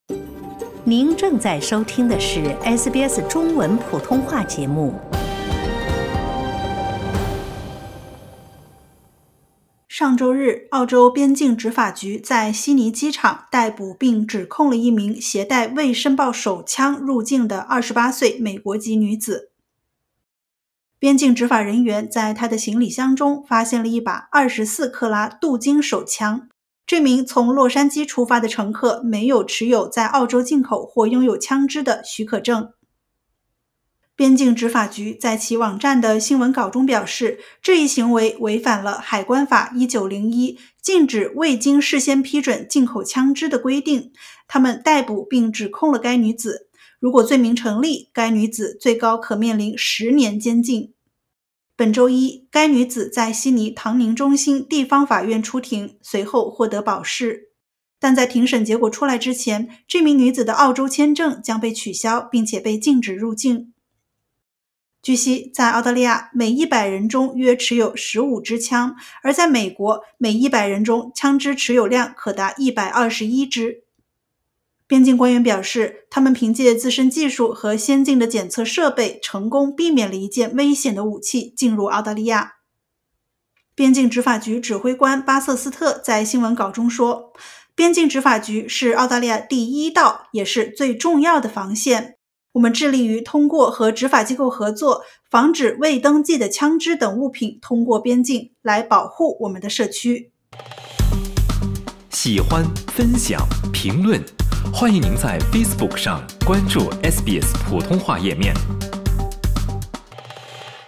【SBS新闻快报】美籍女子因行李中发现镀金手枪在悉尼被捕